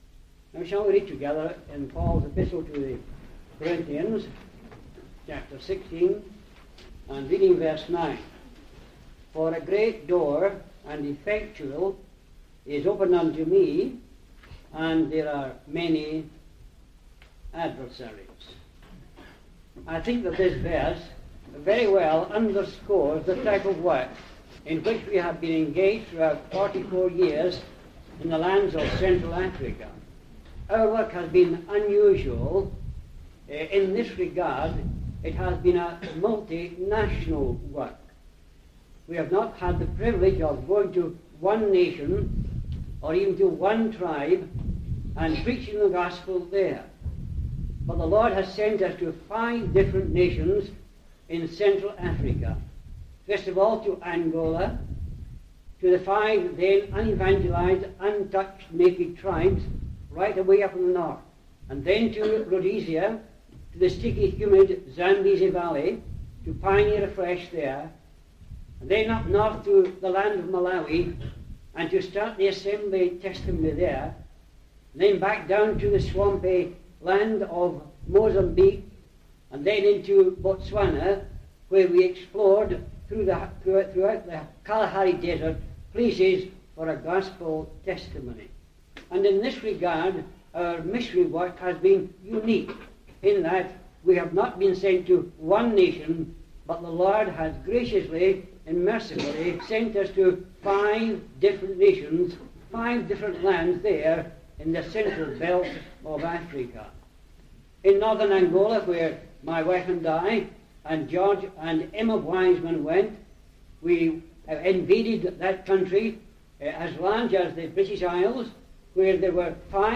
The harsh winter snows meant that the crowd at the famed annual missionary "Report Meeting" in Harley Street Gospel Hall, Glasgow, was much smaller than usual.
Historical Missionary Reports